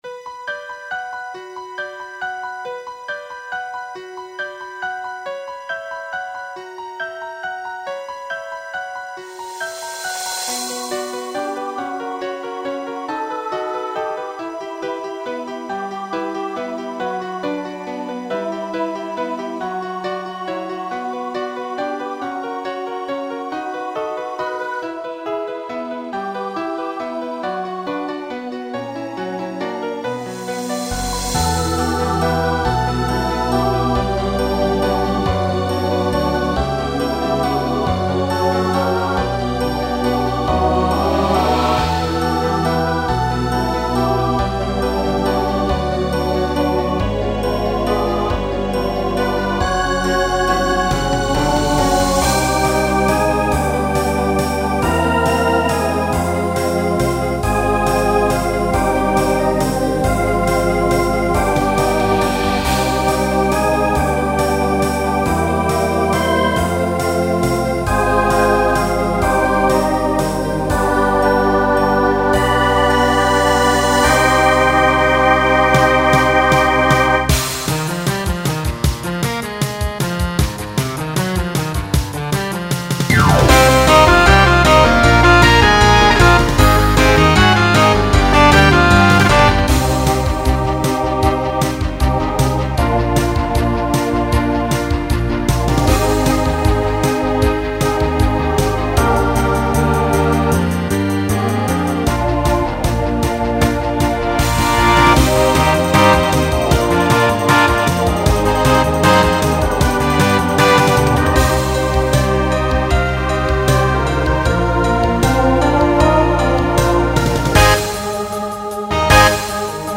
Broadway/Film , Rock Instrumental combo
Voicing SATB